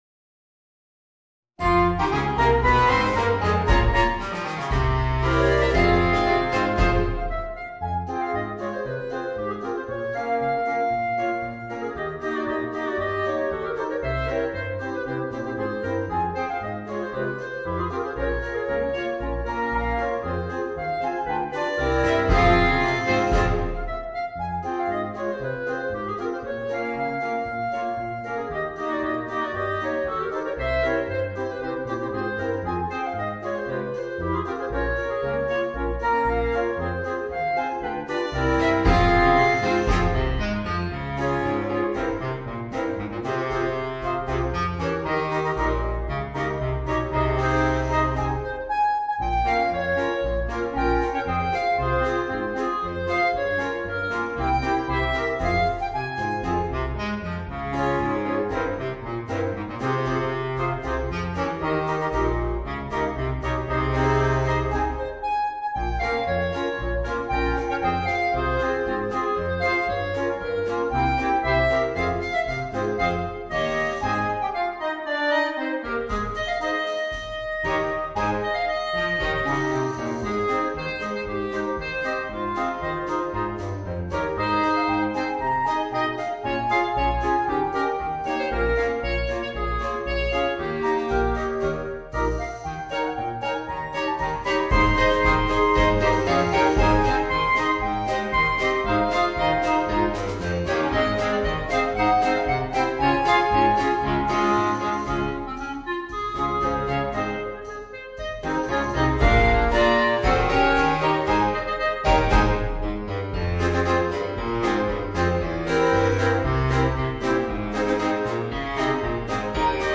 Voicing: 5 Clarinets and Rhythm Section